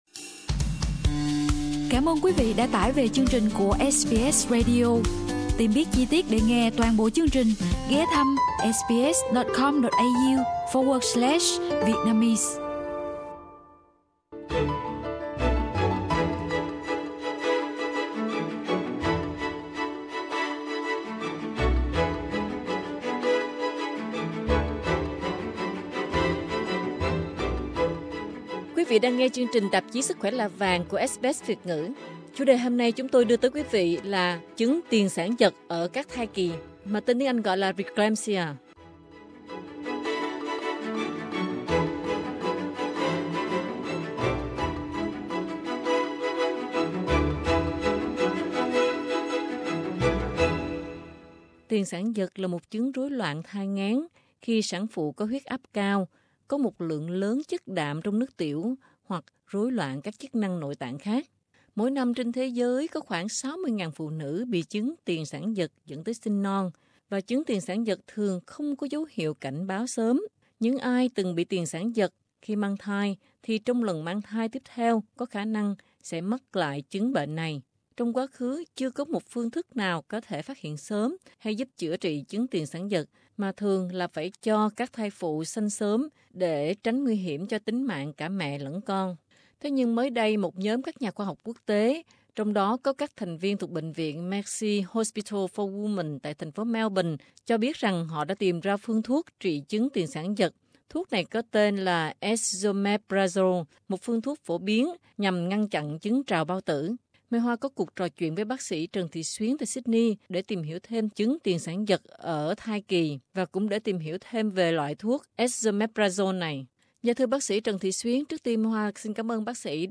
cuộc chuyện trò